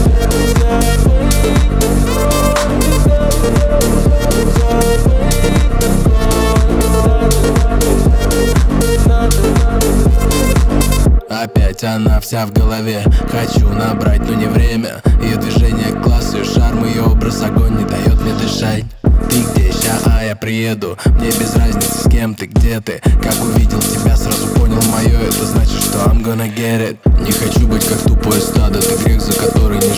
Жанр: Поп музыка / Танцевальные / Русский поп / Русские
Pop, Dance